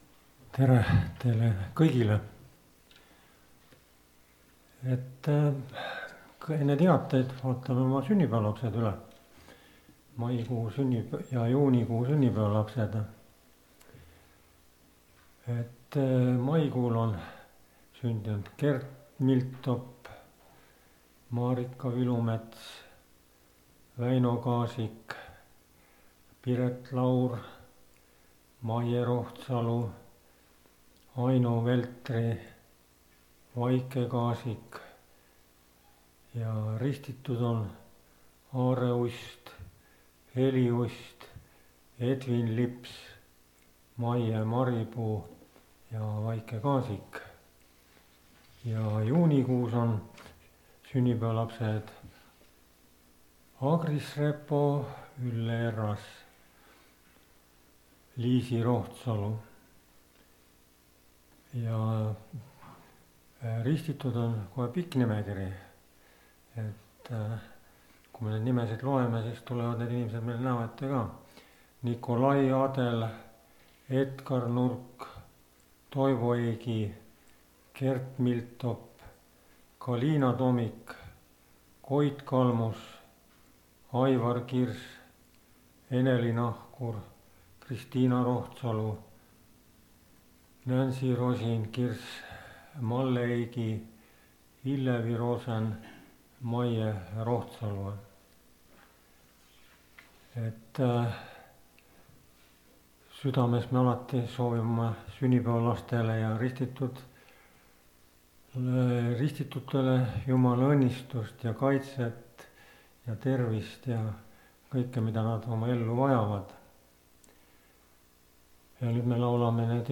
Koosolekute helisalvestused